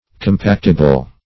Compactible \Com*pact"i*ble\, a. That may be compacted.